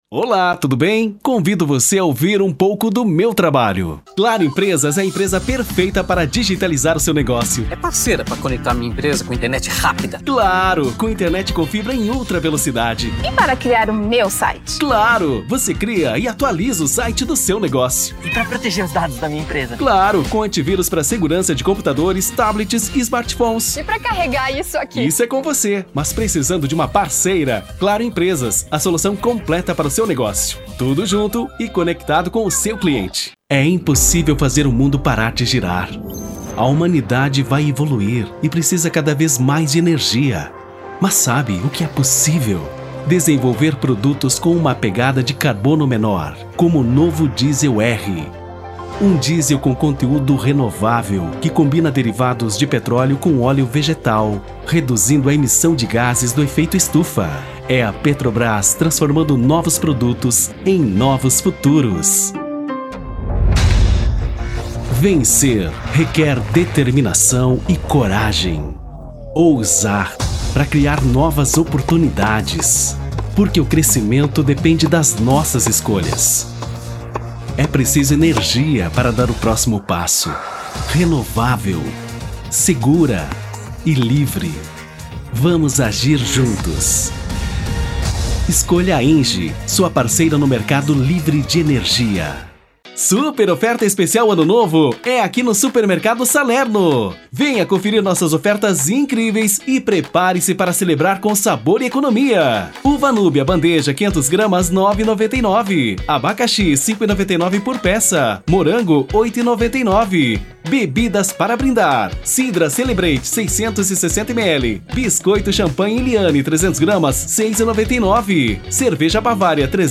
Rio Grande do Sul
Padrão
Animada